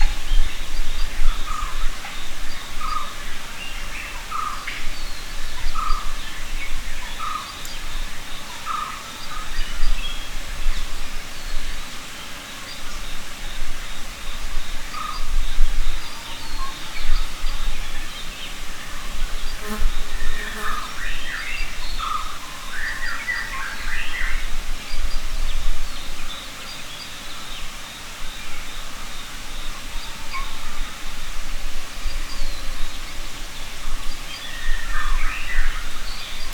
forest-day-4.ogg